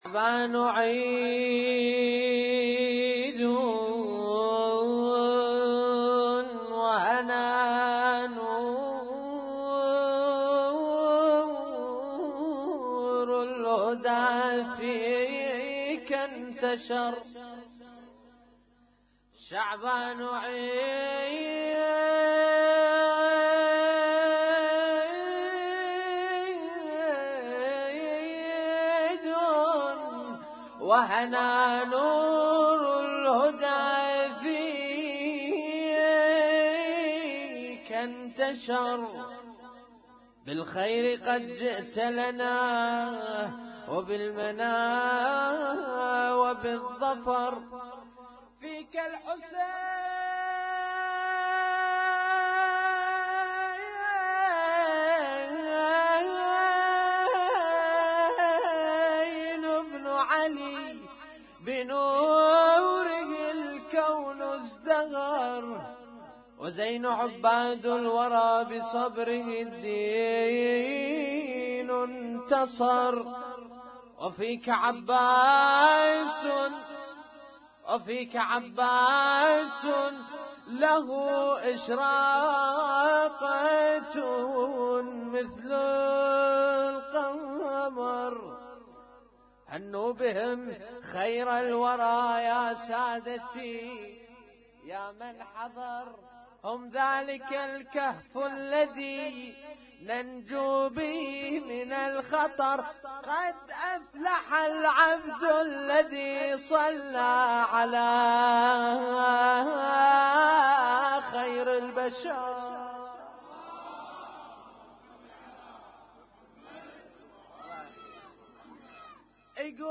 Husainyt Alnoor Rumaithiya Kuwait
اسم التصنيف: المـكتبة الصــوتيه >> المواليد >> المواليد 1438